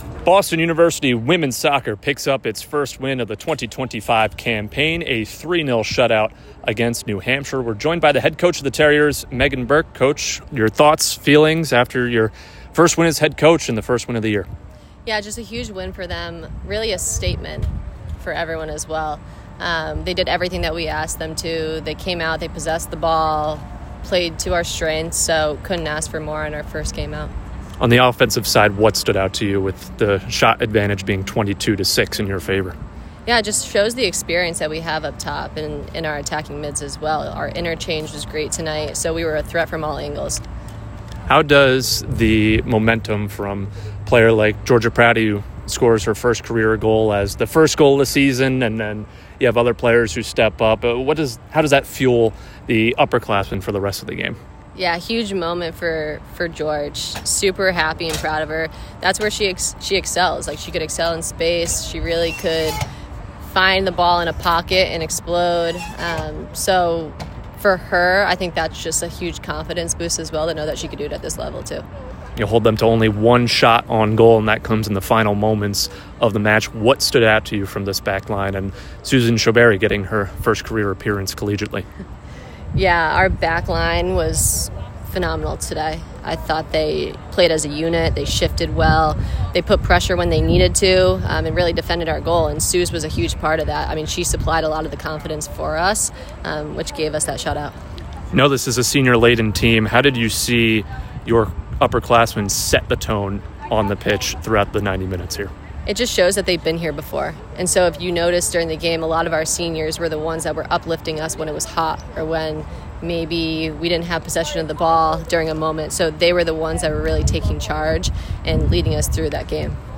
New Hampshire Postgame Interview